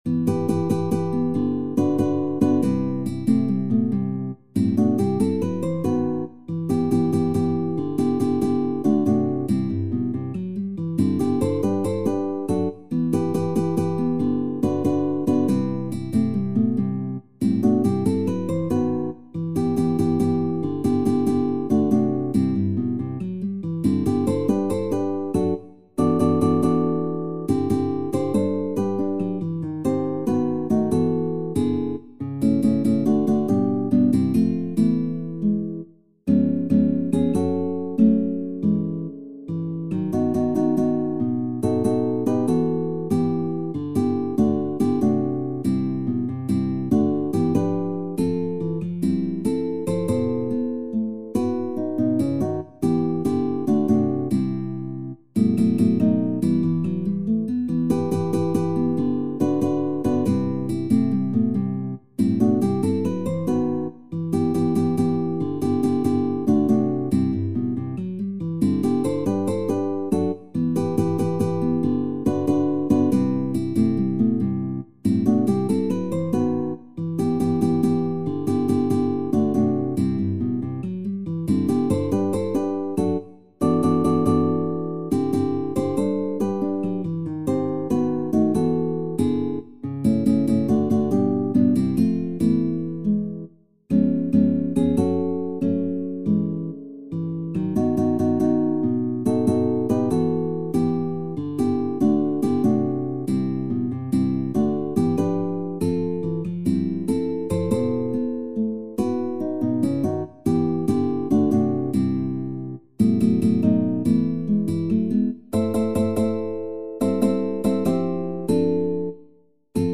SSAA | SATB | SSAB | SSATB
Ein spanischsprachiges Werk voller kubanischer Synkopen